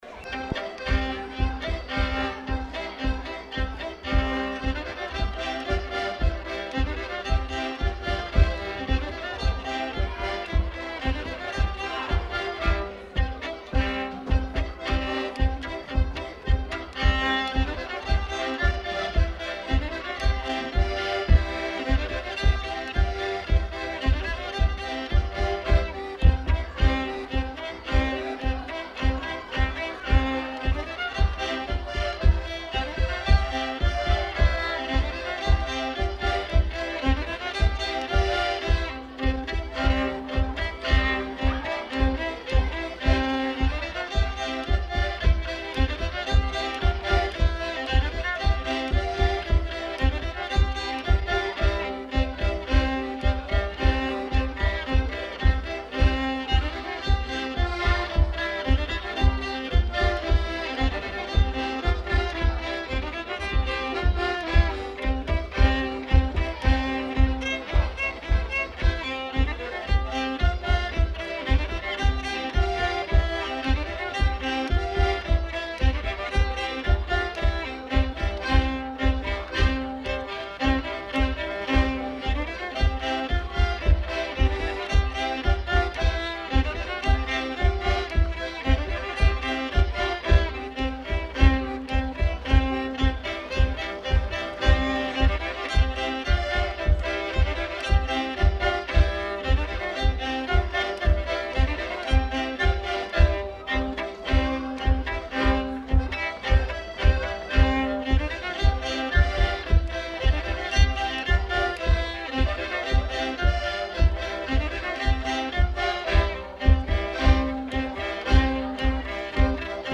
Aire culturelle : Savès
Lieu : [sans lieu] ; Gers
Genre : morceau instrumental
Instrument de musique : violon ; accordéon diatonique
Danse : rondeau